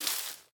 Minecraft Version Minecraft Version 25w18a Latest Release | Latest Snapshot 25w18a / assets / minecraft / sounds / block / azalea_leaves / step4.ogg Compare With Compare With Latest Release | Latest Snapshot
step4.ogg